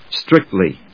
/strík(t)li(米国英語), ˈstrɪktli:(英国英語)/